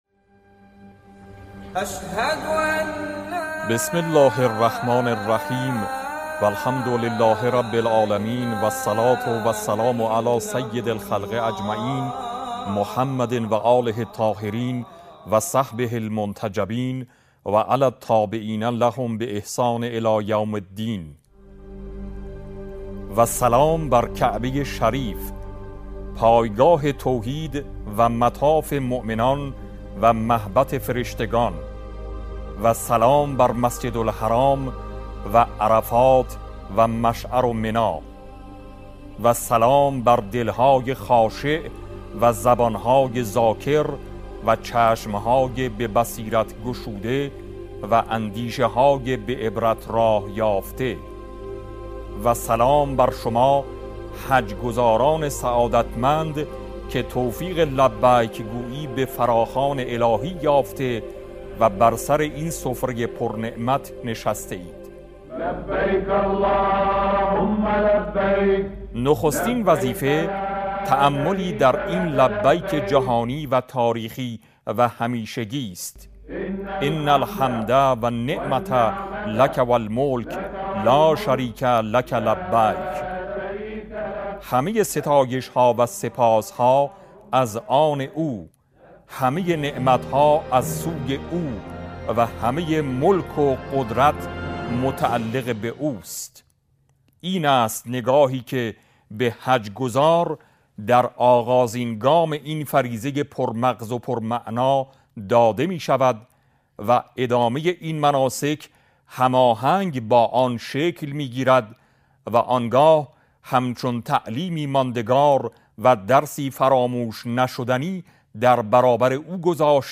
صوت قرائت پیام امام خامنه ای به کنگره حج و حجاج بیت الله الحرام منتشر می شود.